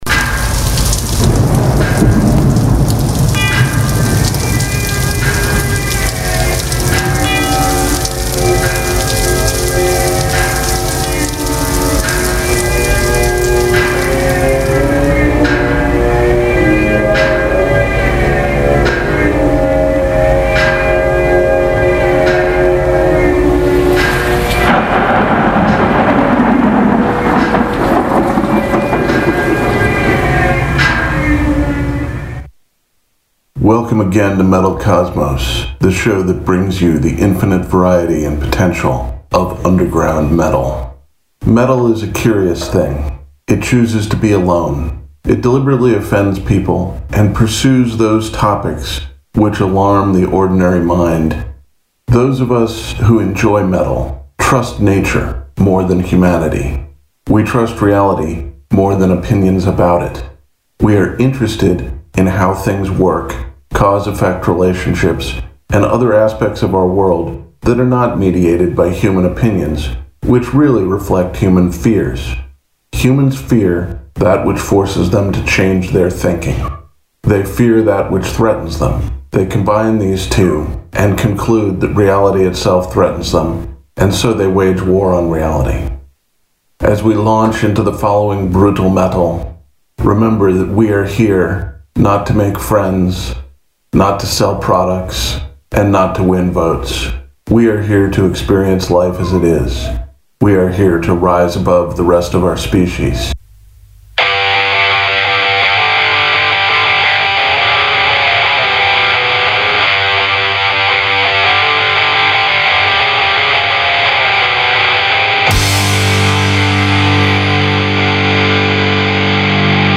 Black Metal , death metal